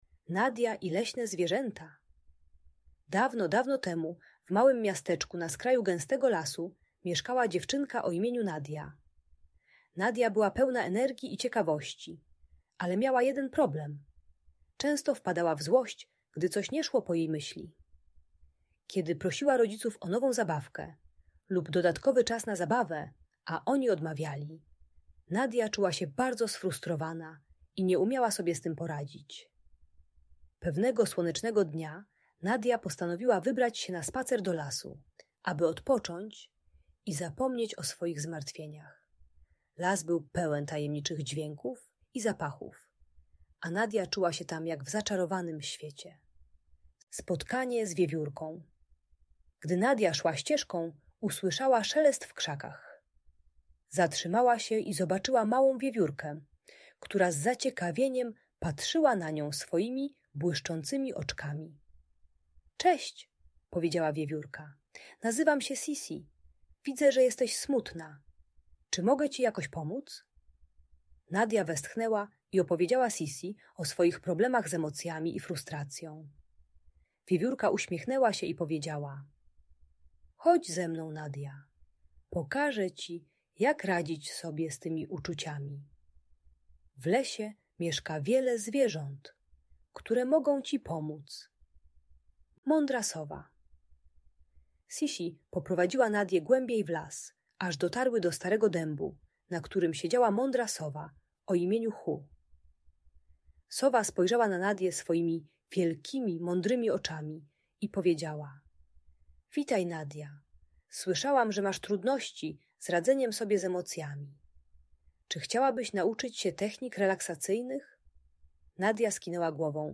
Nadia i Leśne Zwierzęta - Problemy z jedzeniem | Audiobajka